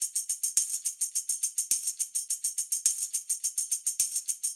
Index of /musicradar/sampled-funk-soul-samples/105bpm/Beats